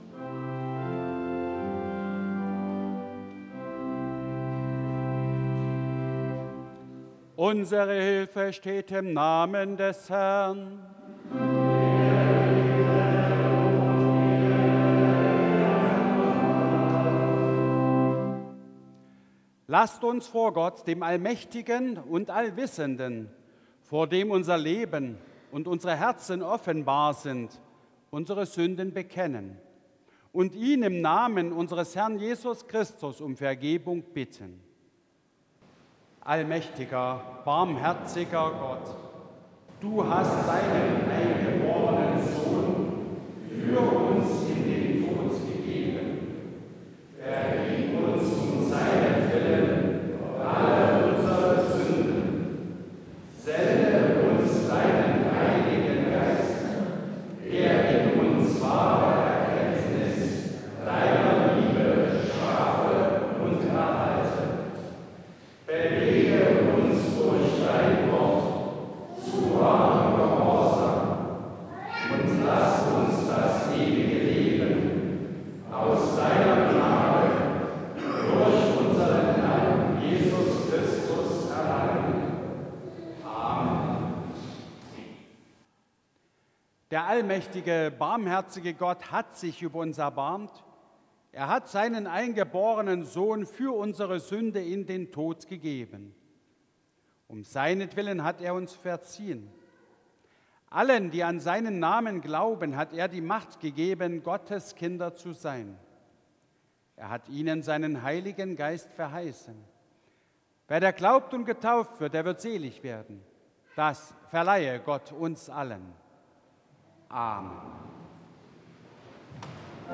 4. Eingangsliturugie Ev.-Luth. St. Johannesgemeinde Zwickau-Planitz
Audiomitschnitt unseres Gottesdienstes am Palmsonntag 2025.